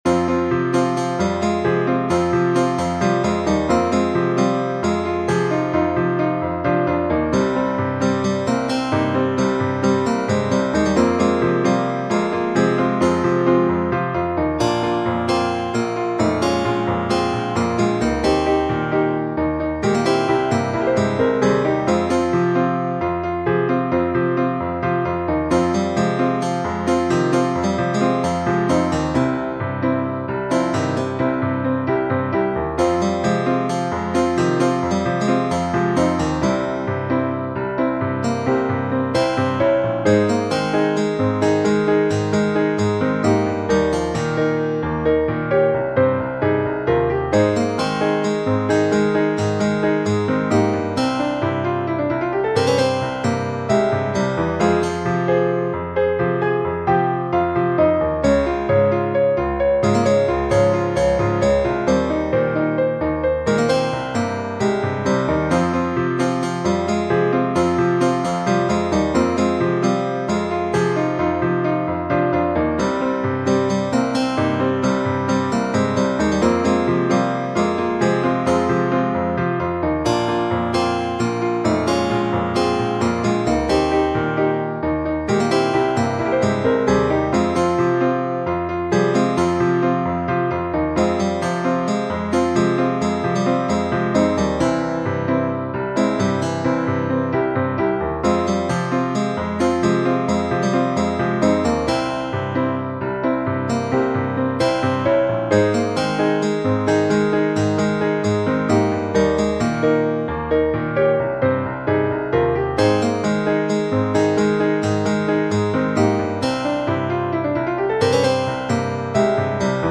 The feature of this piece has got to be the accompaniment.